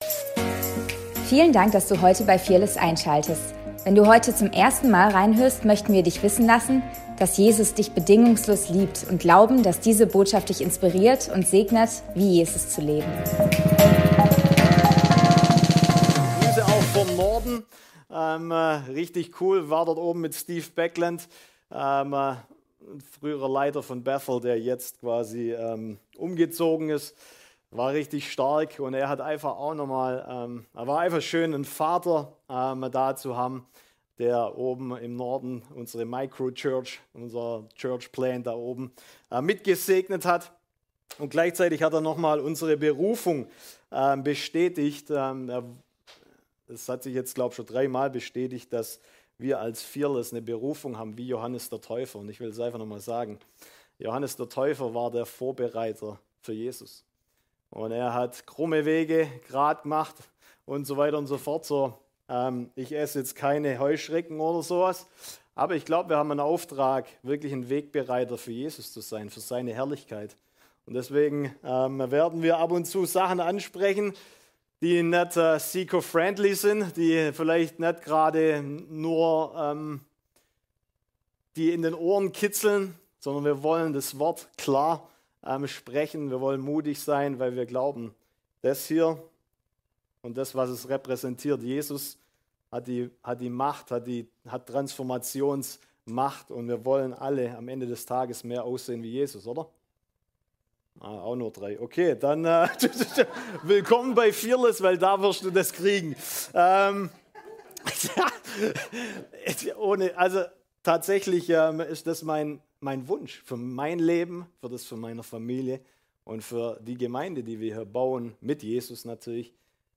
Predigt vom 15.02.2026